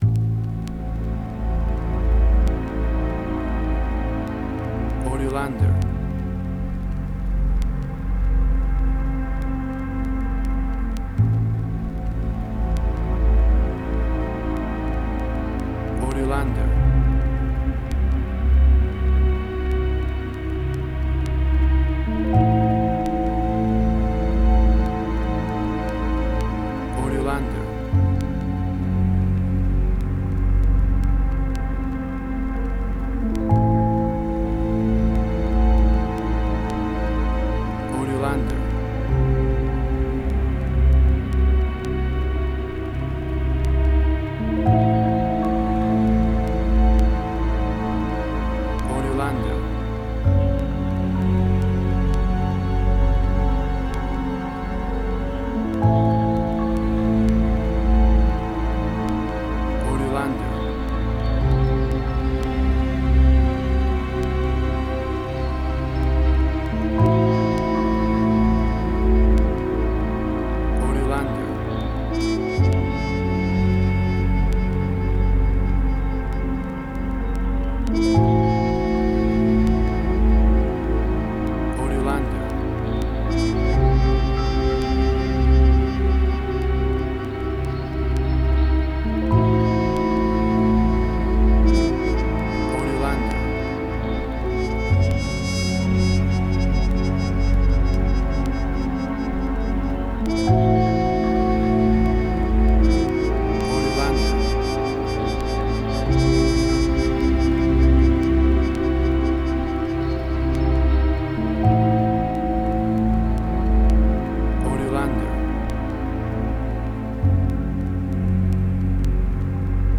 Suspense, Drama, Quirky, Emotional.
Tempo (BPM): 86